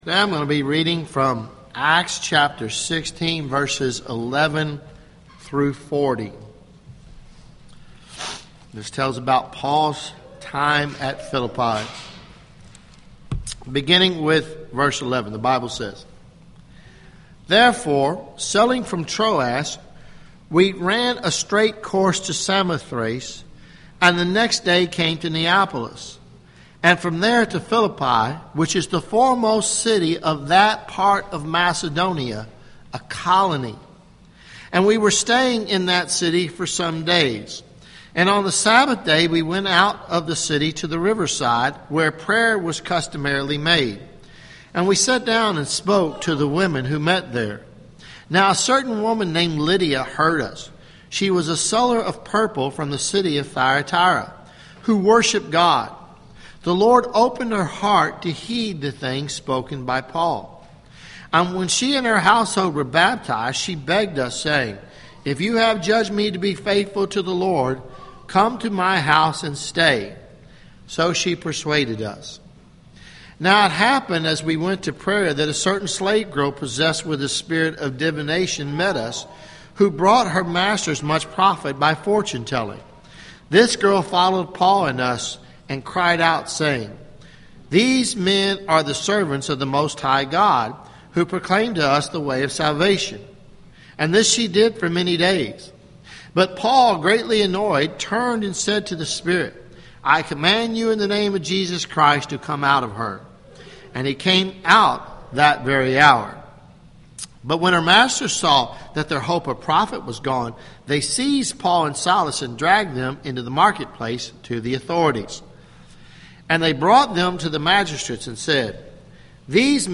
Philippians sermon series – Page 3 – Christ Covenant Presbyterian Church